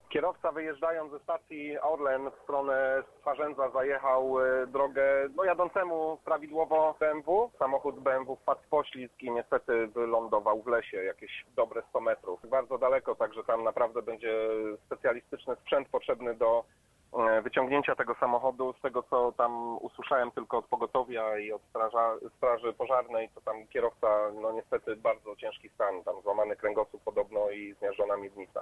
nr4y78leytc0uu4_swiadek-wypadku-o-zdarzeniu.mp3